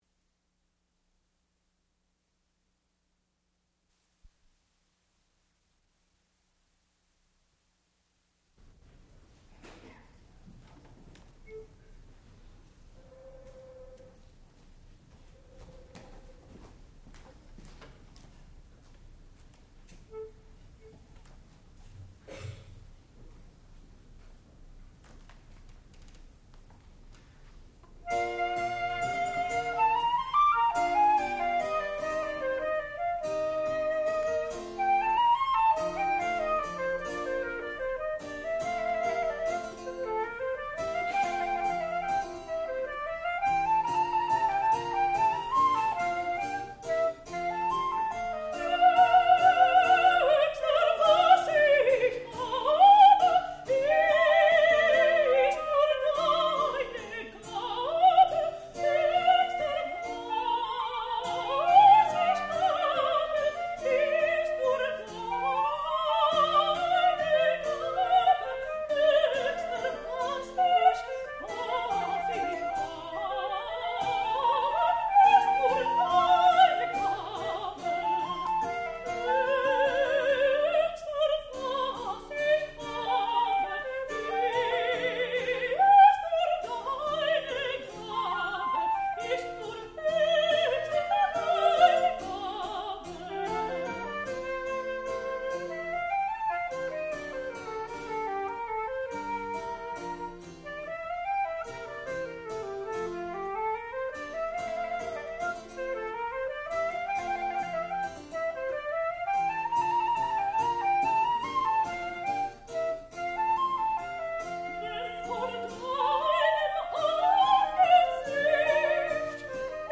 sound recording-musical
classical music
harpsichord
soprano
flute